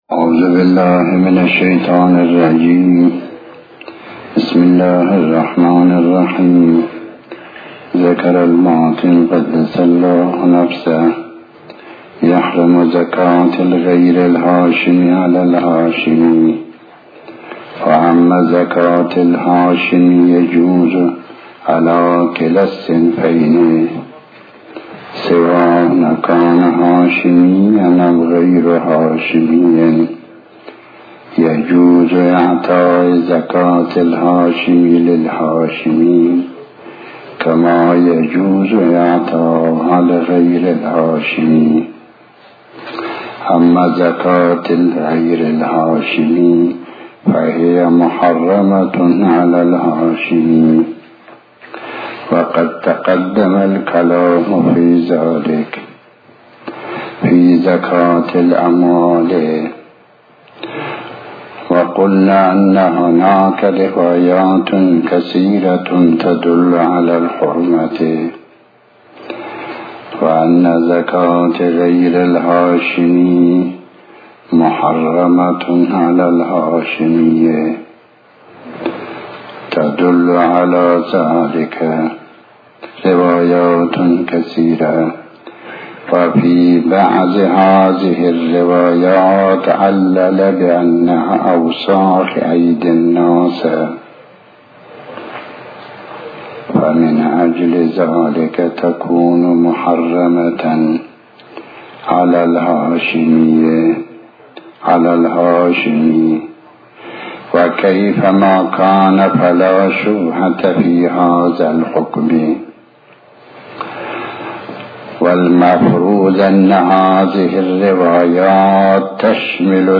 تحمیل آیةالله الشيخ محمداسحاق الفیاض بحث الفقه 38/06/15 بسم الله الرحمن الرحيم الموضوع:- زكاة الفطرة.